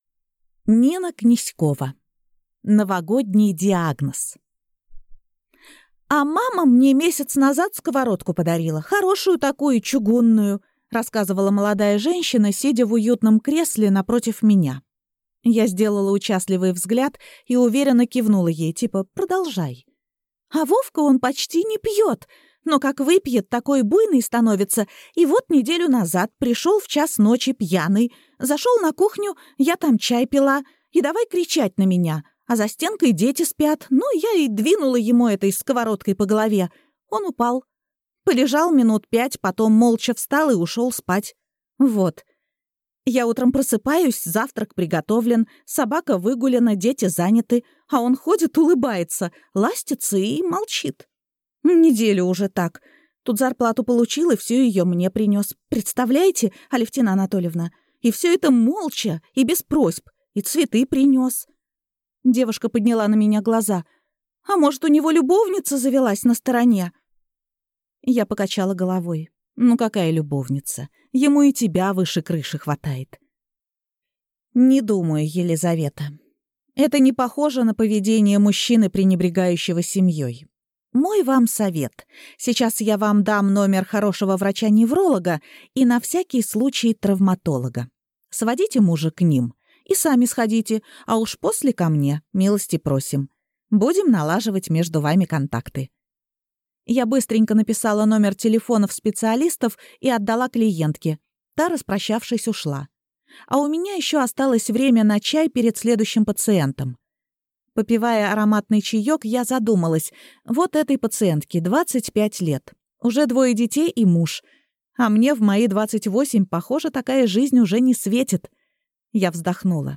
Аудиокнига Новогодний диагноз | Библиотека аудиокниг
Прослушать и бесплатно скачать фрагмент аудиокниги